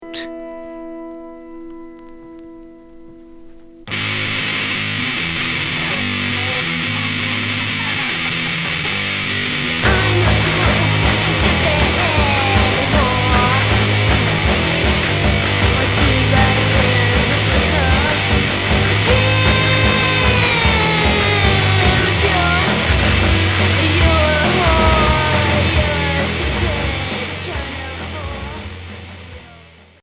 Noisy pop